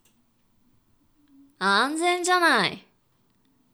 josei_anzenjanai.wav